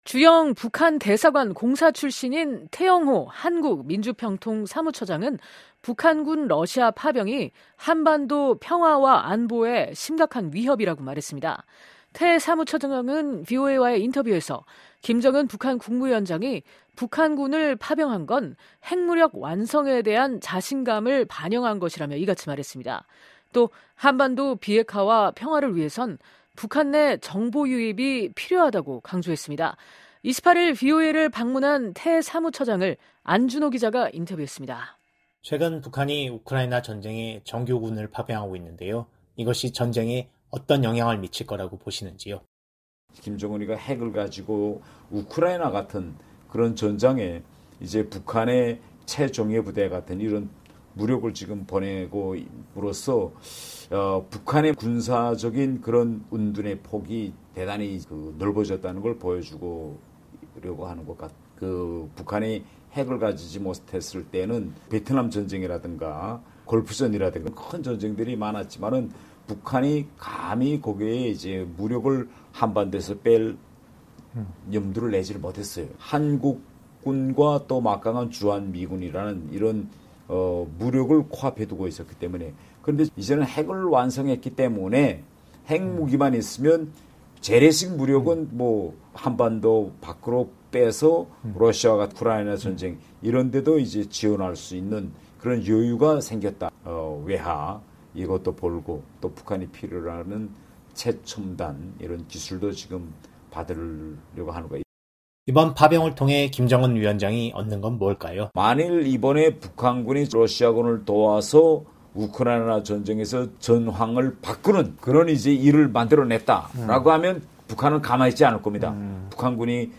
[인터뷰: 태영호 사무처장] “김정은, 러 파병으로 자신감∙∙∙심각한 위협”